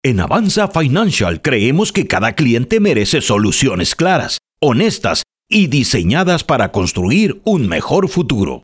Corporate & Industrial Voice Overs
Spanish (Mexican)
Adult (30-50) | Older Sound (50+)